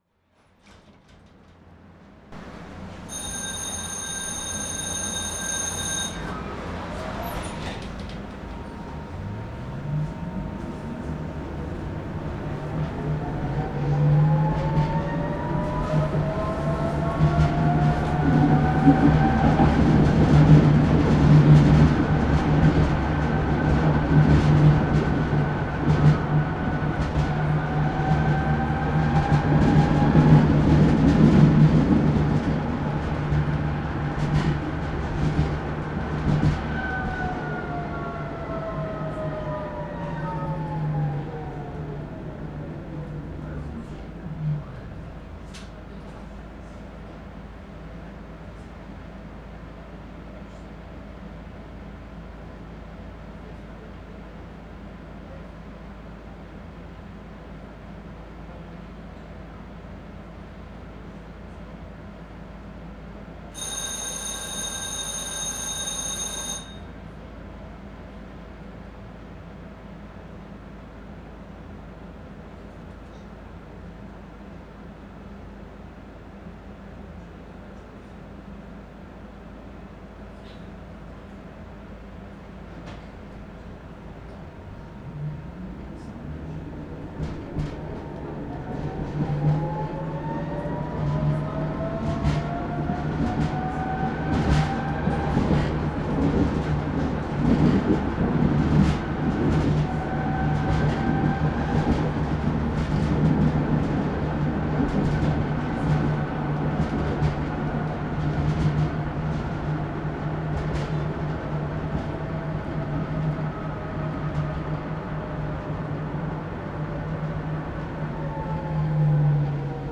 Streetcar (same type with another steering mechanism without buzzing sound)